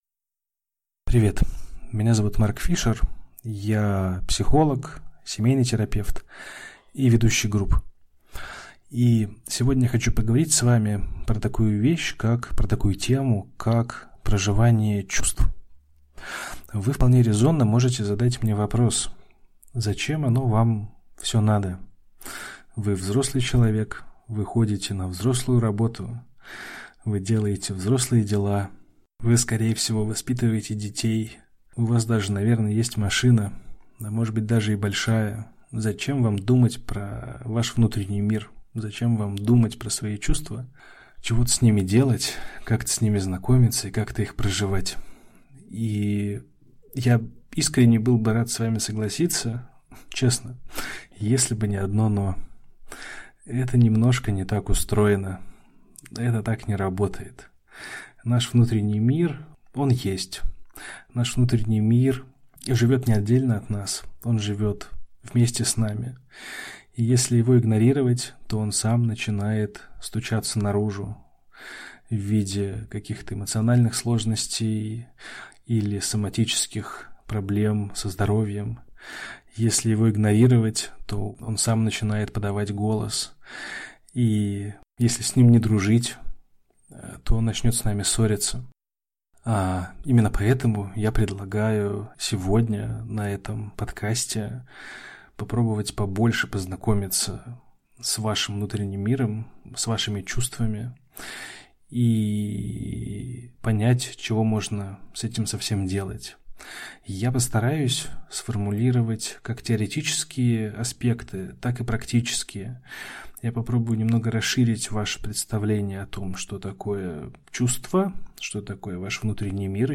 Аудиокнига Аптечка психологической самопомощи: Работа с чувствами | Библиотека аудиокниг